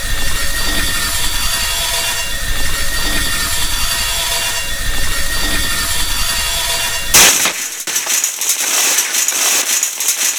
wire_cut_long.ogg